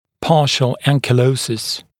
[‘pɑːʃl ˌæŋkɪˈləusɪs][‘па:шл ˌэнкиˈлоусис]частичный анкилоз